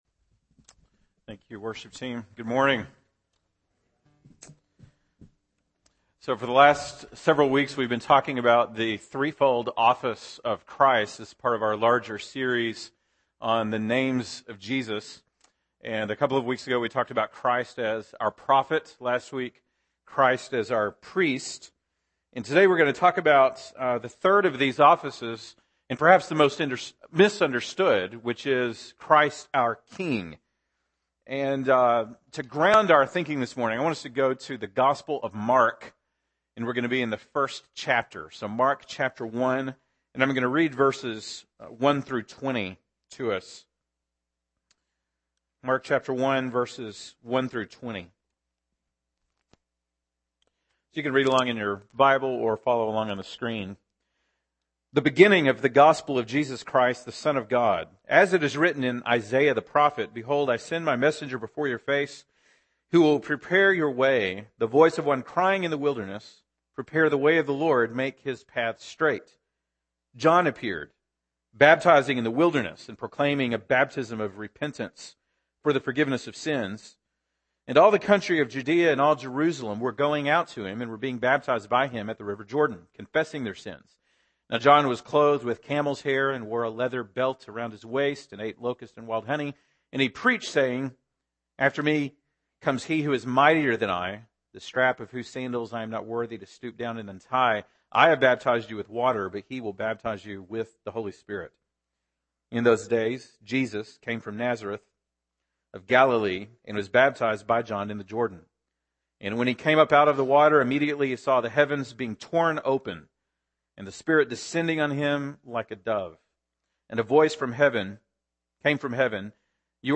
February 28, 2016 (Sunday Morning)